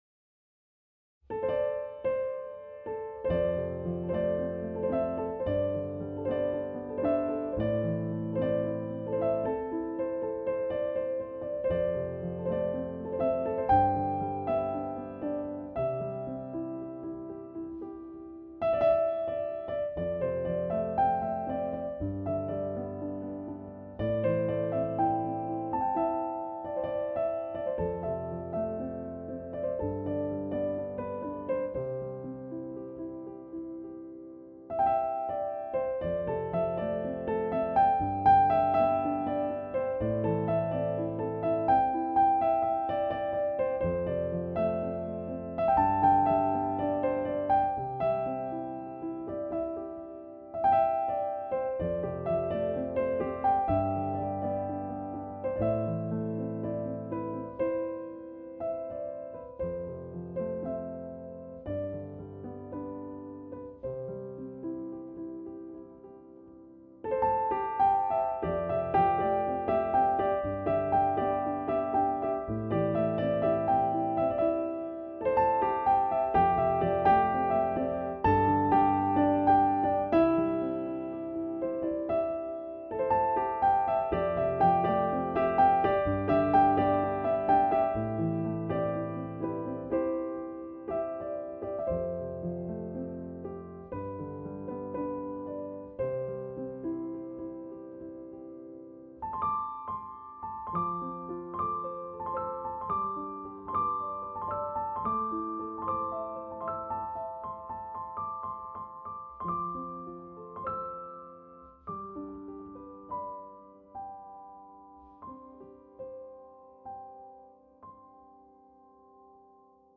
Finally I use Pianoteq for debugging before publishing my music.
For the composition of the piece, I used a triplet-based main melody and repeated the passage to construct the melody of the water waves.